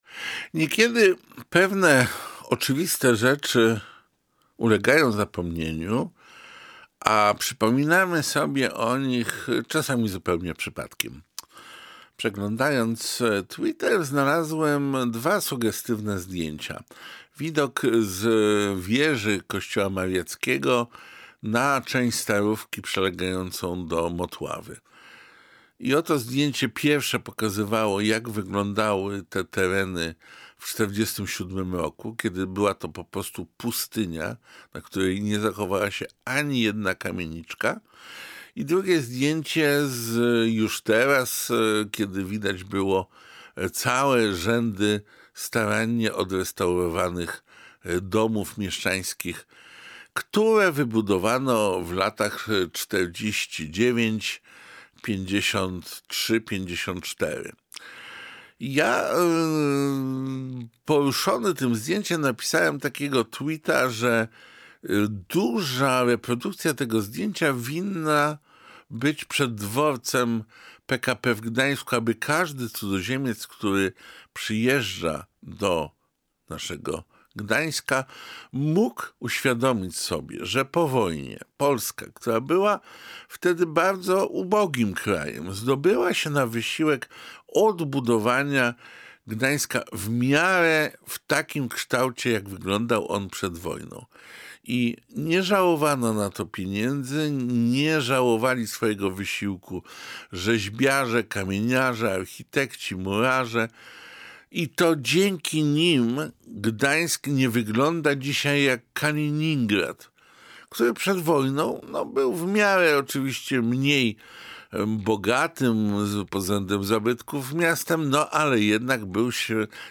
Niekiedy pewne oczywiste rzeczy ulegają zapomnieniu, a przypominamy sobie o nich czasami zupełnie przypadkiem – mówi Piotr Semka na wstępie swojego felietonu, w którym postuluje utworzenie w Gdańsku nowego muzeum.